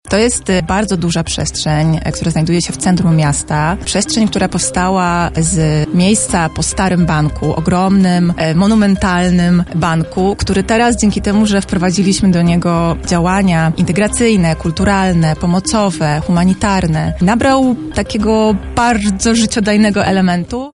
Gościni Porannej Rozmowy Radia Centrum opowiedziała również o Prawach Dziecka z okazji zbliżającego się ich święta.